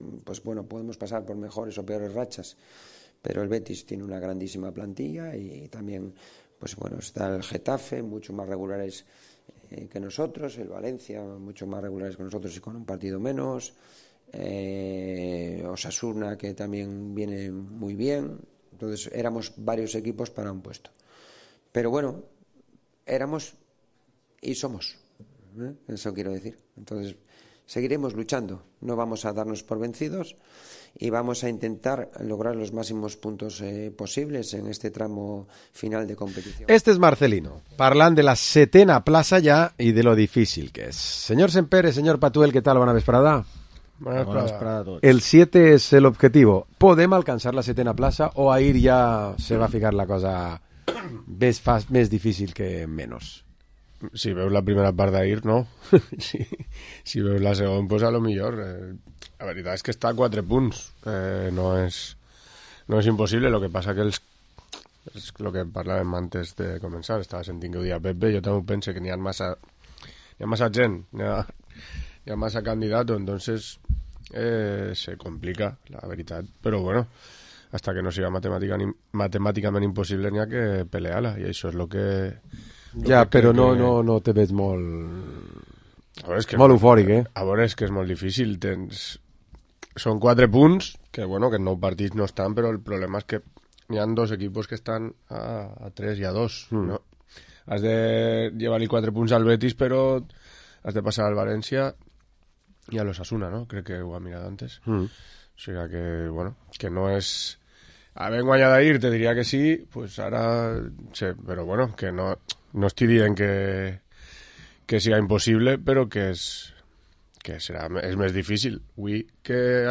Programa esports tertúlia dimarts 2 d’abril